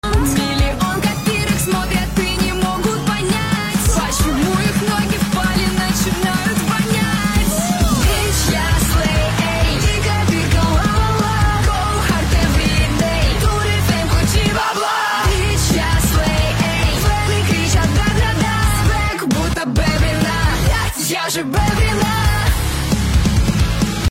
это же звучит как нейронка
поч у нее везде голос разный
почему ее голос как ии звучит
почему голос как будто нейронка